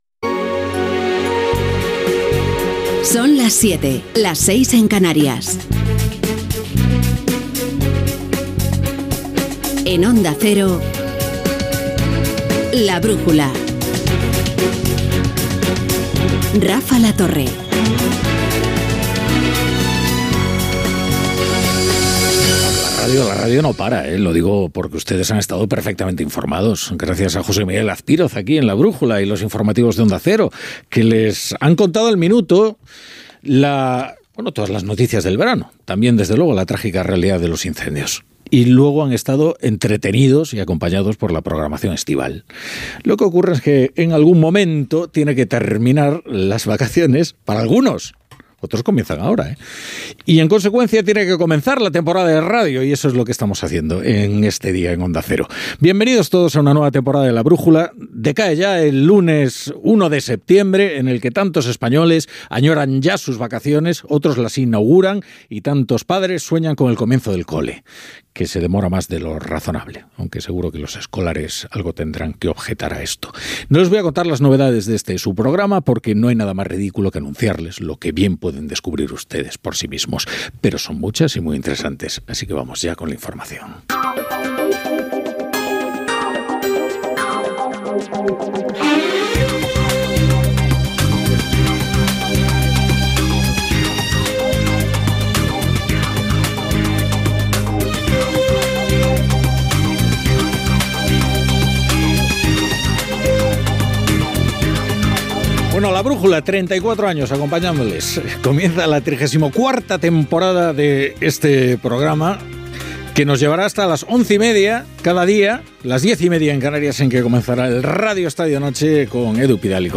Careta del programa, presentació, repàs a les notícies del dia.
Informatiu